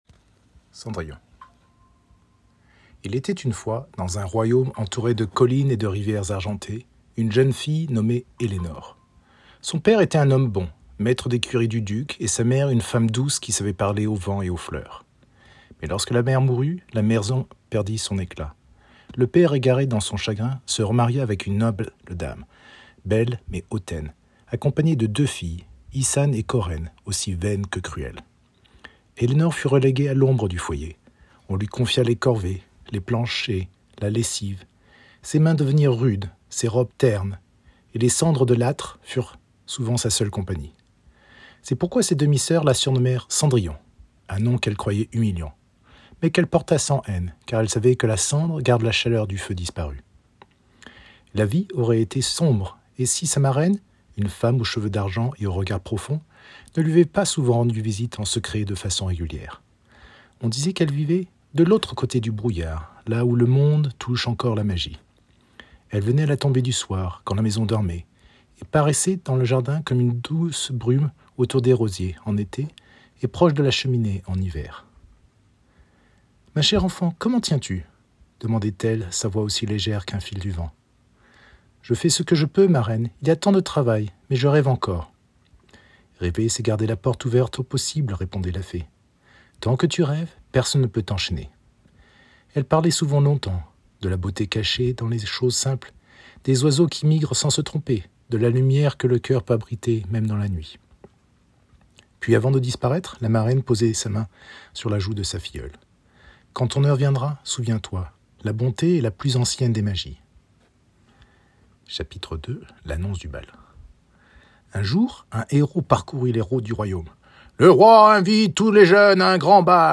Genres: Kids & Family, Stories for Kids